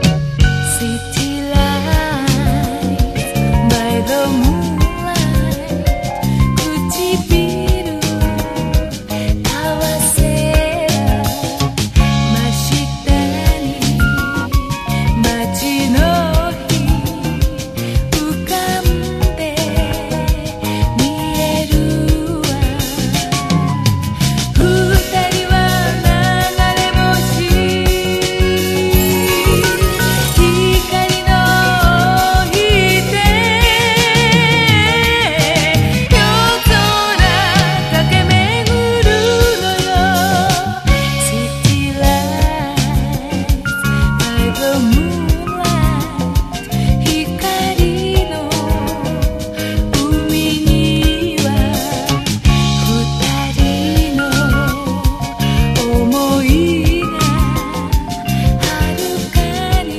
LOUNGE
JAPANESE EASY LISTENING
ファンキー・ブラスが降り注ぐ